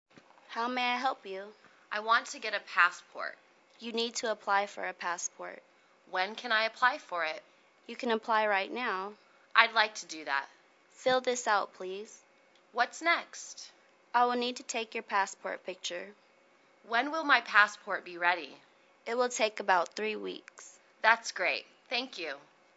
英语情景对话-Applying for a Passport(2) 听力文件下载—在线英语听力室